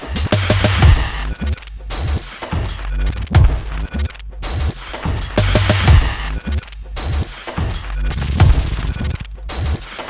描述：一个带有轻微偏色的节奏的环境节拍。
Tag: 95 bpm Weird Loops Drum Loops 1.70 MB wav Key : Unknown